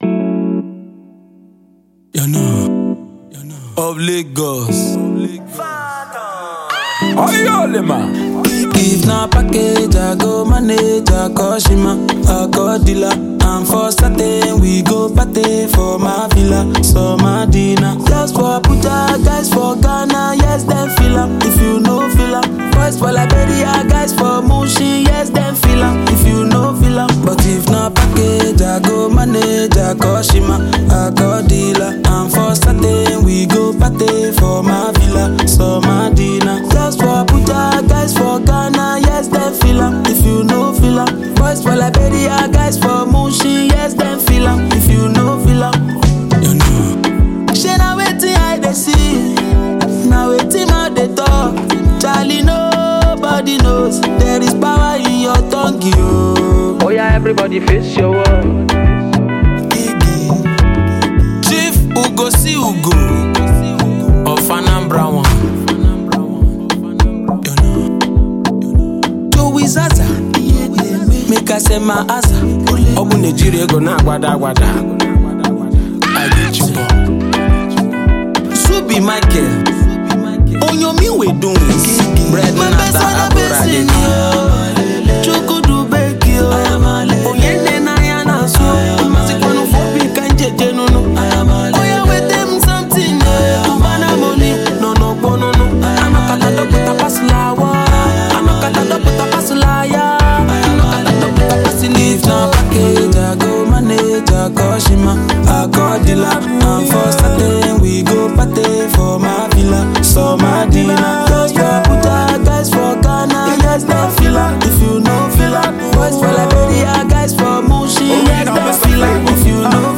A-List Nigerian singer and songwriter